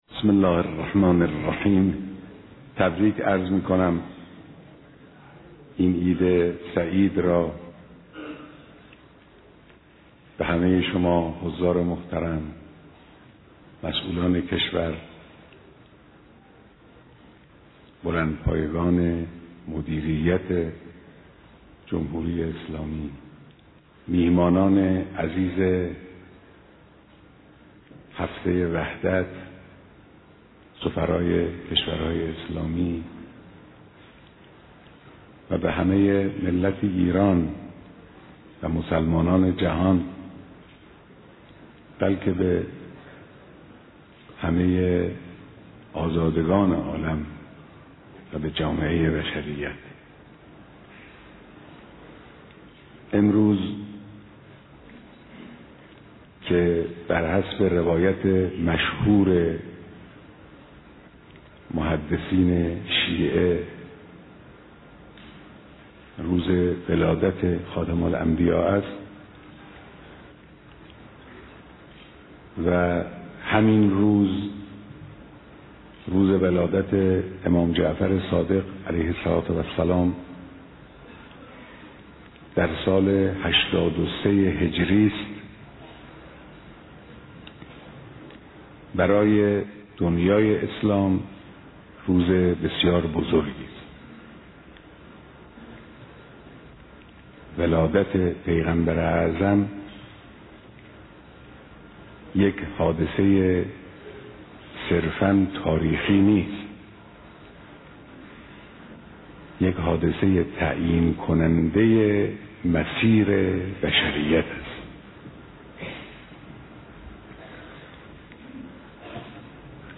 دیدار مسؤولان نظام در خجسته سالروز میلاد پیامبر اعظم (ص)